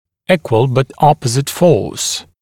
[‘iːkwəl bət ‘ɔpəzɪt fɔːs][‘и:куэл бат ‘опэзит фо:с]равная, но противоположно направленная сила